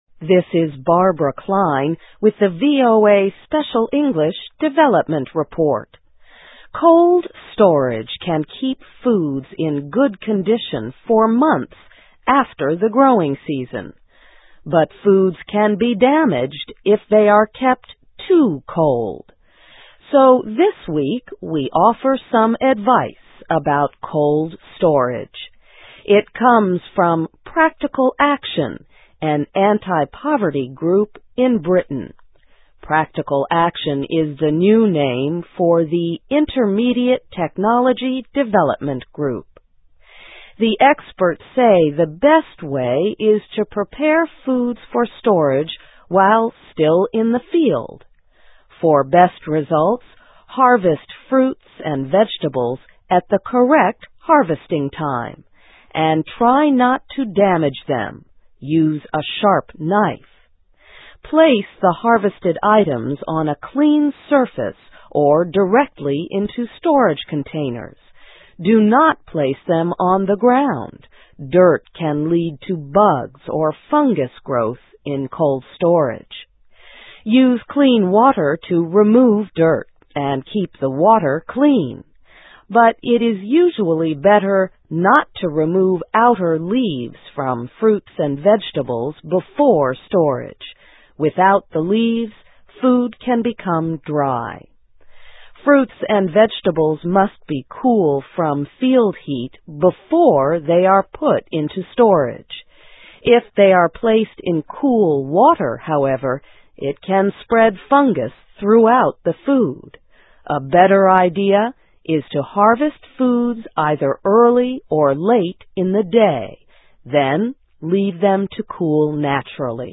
Keep Fruits and Vegetables Cold, But Not Too Cold (VOA Special English 2005-08-07)